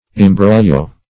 Imbroglio \Im*brogl"io\, n.; pl. Imbroglios. [Written also
imbroglio.mp3